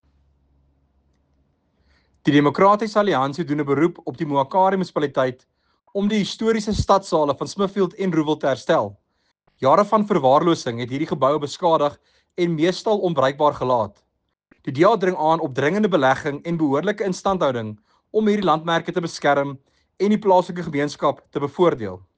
Afrikaans soundbite by Werner Pretorius MPL, and